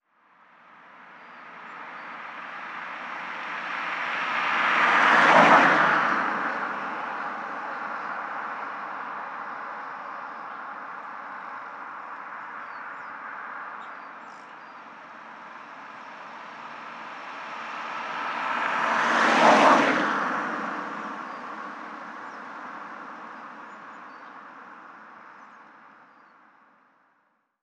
Dos coches seguidos pasando
coche
Sonidos: Transportes